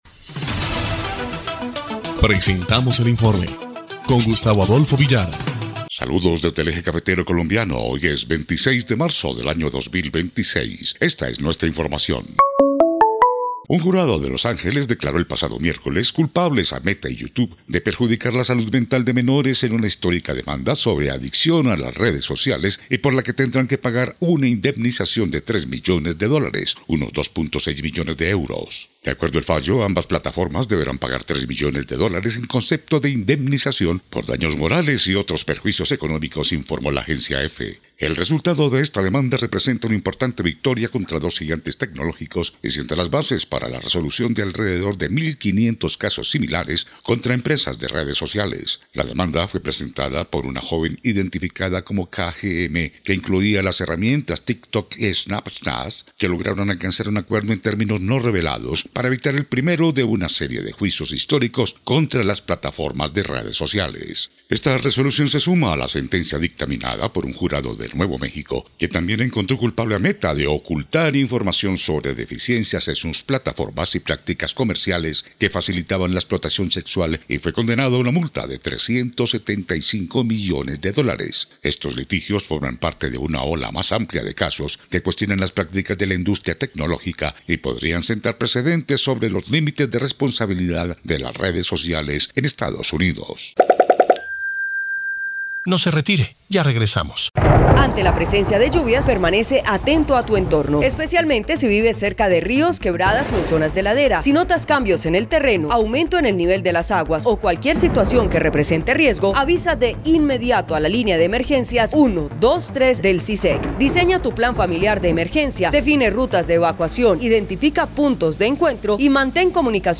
EL INFORME 3° Clip de Noticias del 26 de marzo de 2026